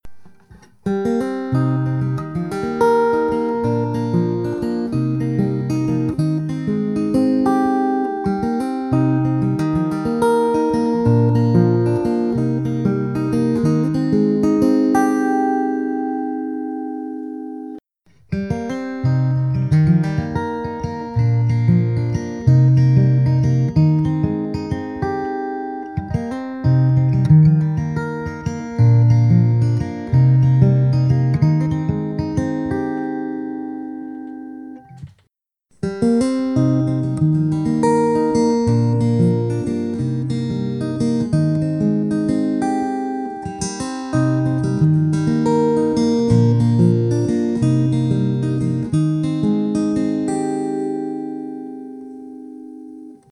Abgenommen mit einem Shure SM57, direkt vorm Schertler Jam 100. EQ's alle neutral. Wenn man beim Taylor ES2 genau hinhört, kann man die von mir oben beschriebene Spielgeräusche (Störgeräusche) wahrnehmen.
Das M80 trägt mit seiner "3D Abtastung" für meinen Geschmack ein bisschen zu viel künstliche Natürlichkeit (heller Klang) auf. Das M1 hingegen hat mich echt beeindruckt. Ein schöner, ausgewogener Klang, der mit Sicherheit nicht so natürlich klingt, wie z.B. ein Mikrofon es könnte... aber doch eben gut genug für meinen Geschmack um nicht als zu unnatürlich (oder elektrisch) durchzugehen.